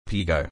Pronunciation
piː ɡəʊ